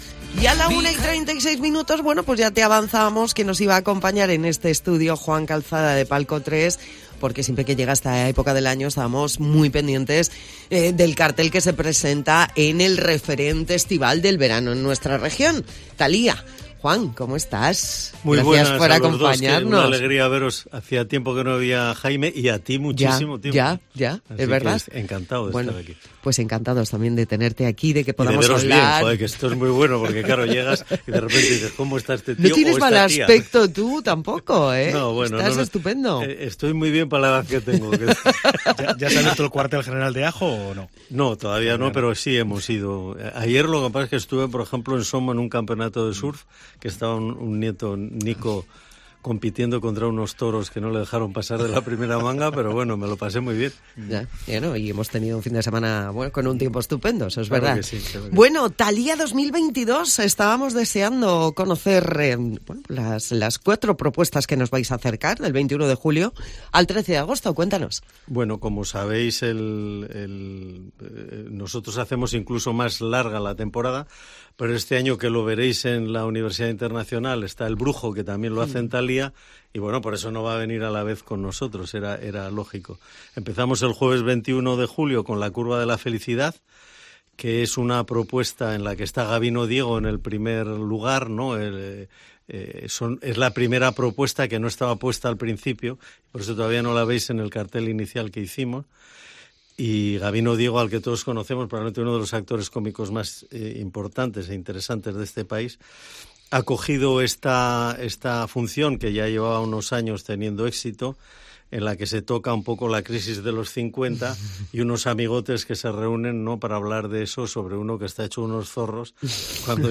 presentar en Cope Cantabria las obras participantes